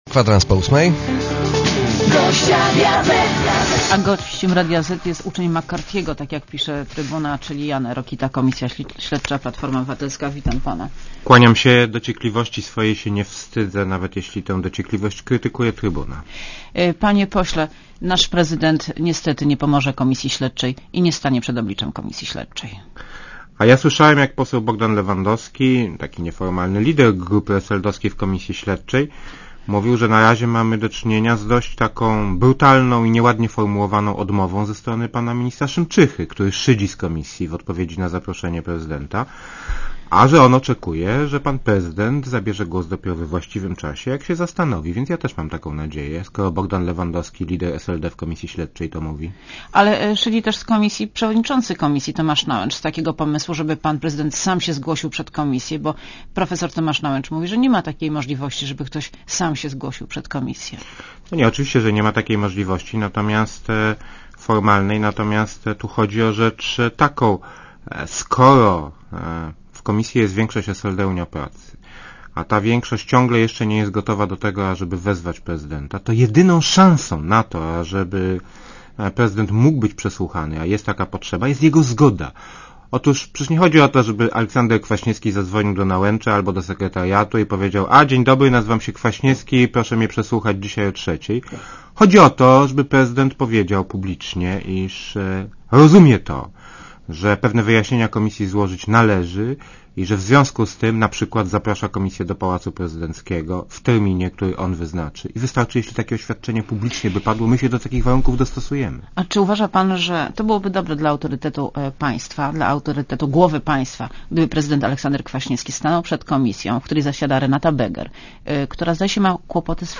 Posłuchaj wywiadu Panie pośle, nasz prezydent niestety nie pomoże komisji śledczej i nie stanie przed jej obliczem.